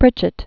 (prĭchĭt), Sir V(ictor) S(awdon) 1900-1997.